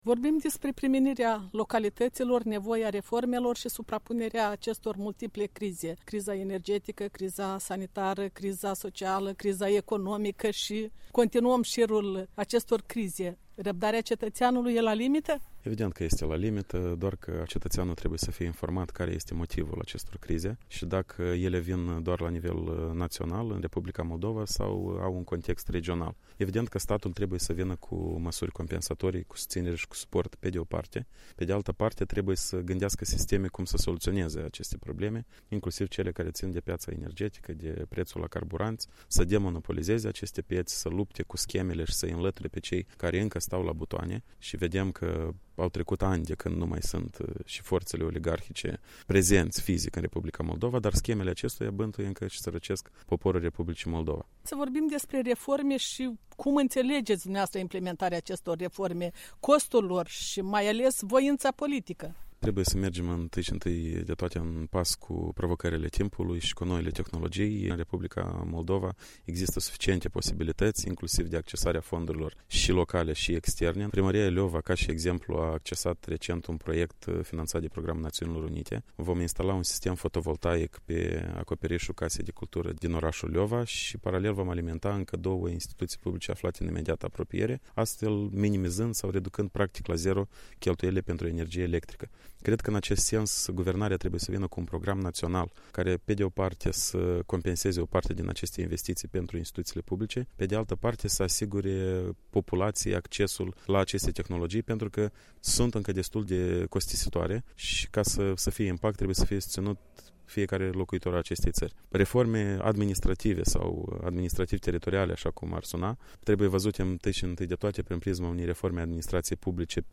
în dialog cu primarul din Leova, Alexandru Bujorean